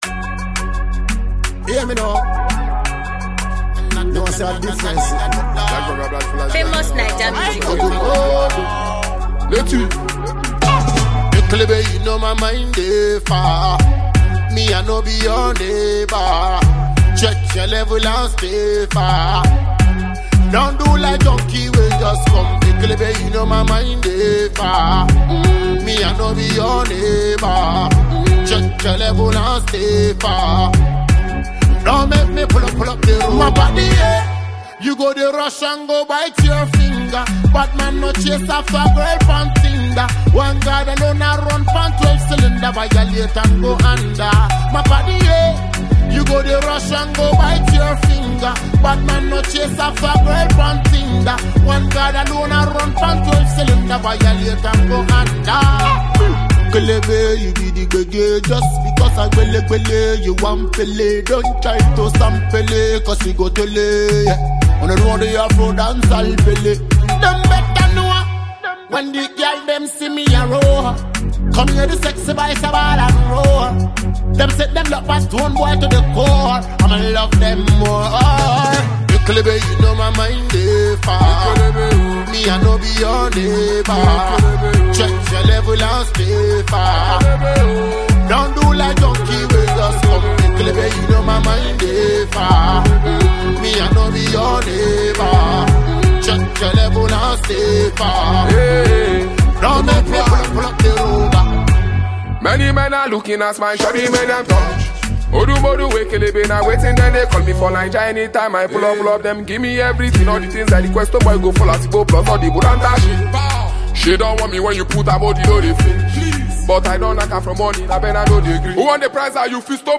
a performer, rapper, and writer from Nigeria